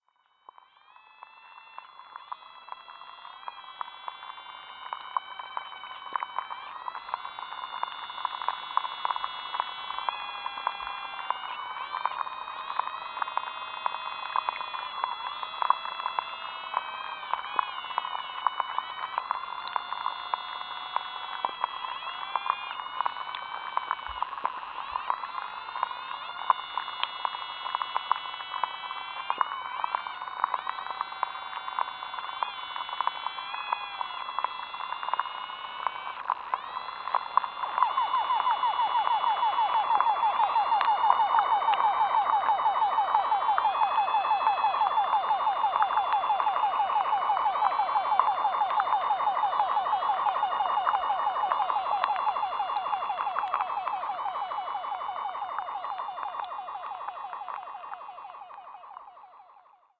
Another consideration is that no mechanical devices were operating on the Fen during the period that these recordings were made. The recordings are not contaminated by any electrical interference. Other than an occasional overhead aircraft, no other sounds from above the water are present in the recordings.
Each of these water insects are known to produce sound through a process called stridulation.
The recordings here were mostly done with hydrophones placed closed to the insect subjects, and apart from the odd sound of aircraft this recording features just recordings of the insects in their natural habituate.